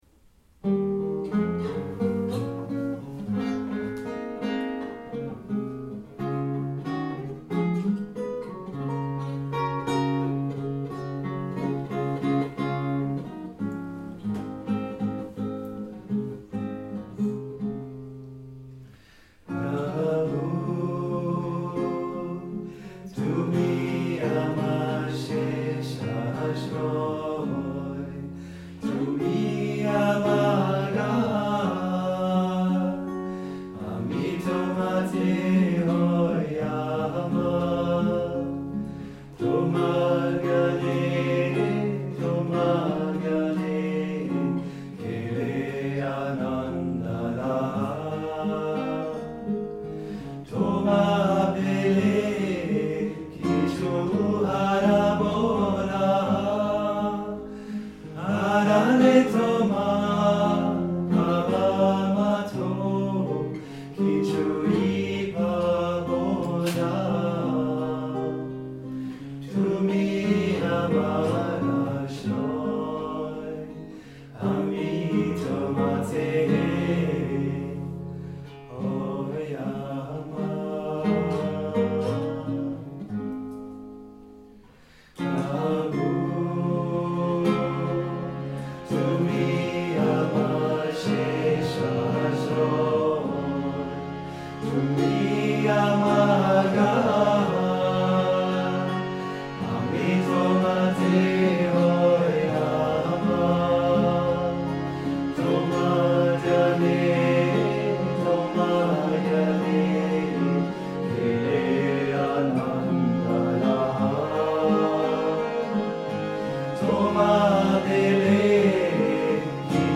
In October 2018, the music group Mahashakti – a group of boys from Austria and Germany – gave a concert of Sri Chinmoy’s meditation music arranged for guitar, harmonium and voice in Salzburg, Austria.